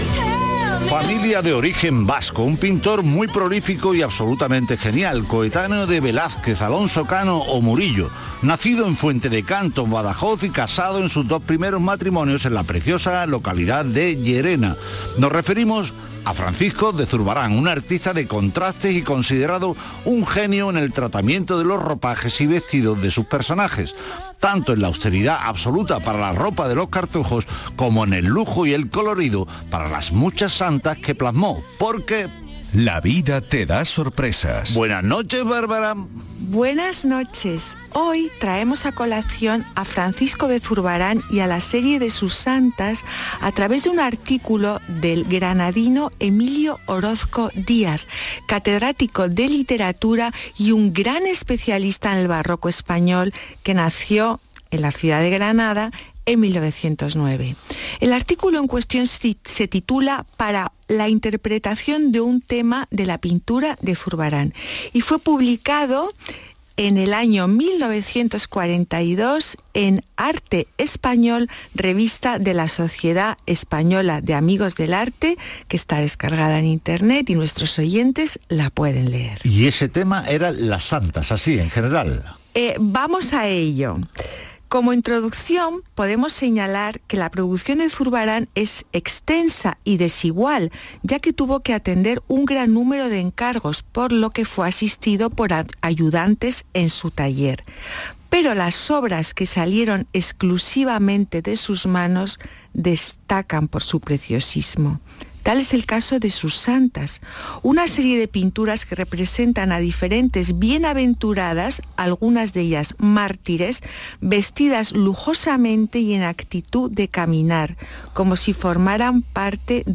Aquí os dejo mi intervención en el programa de Radio Andalucía Información, «Patrimonio andaluz» del día 11/06/2023